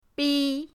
bi1.mp3